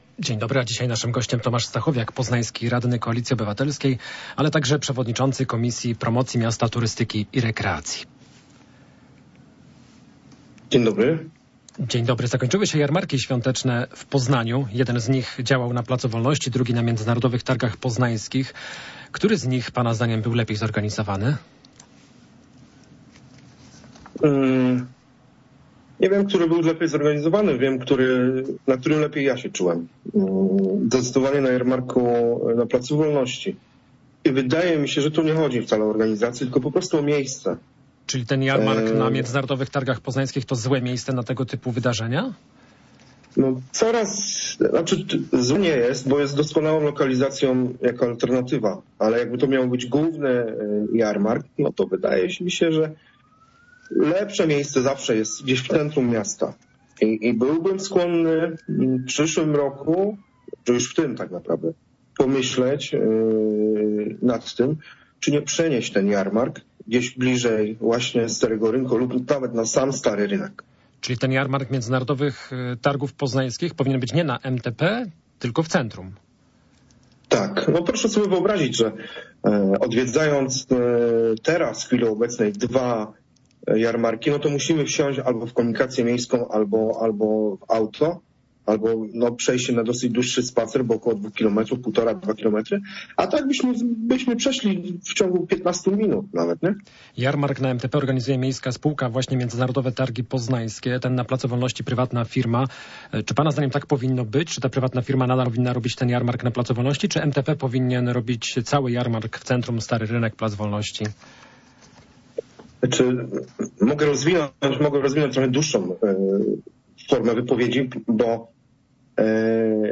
Gościem jest przewodniczący komisji promocji, poznański radny Tomasz Stachowiak z KO.